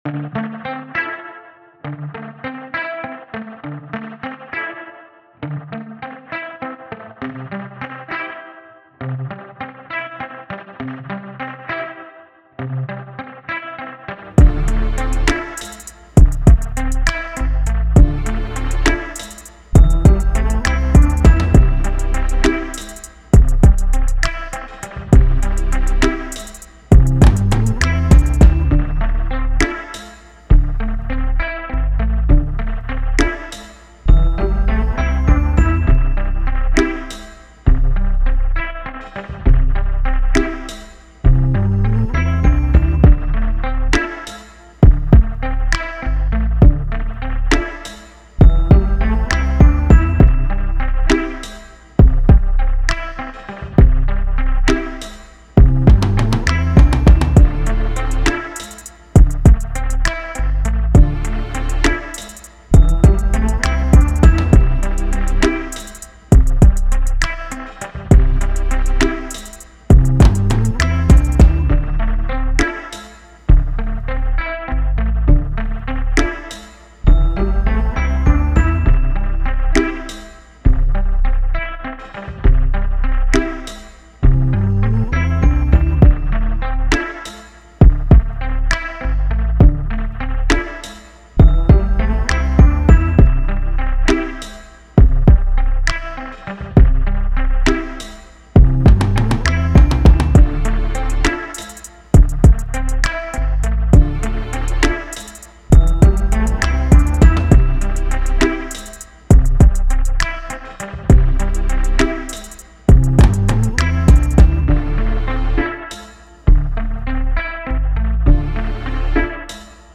R&B
Cmaj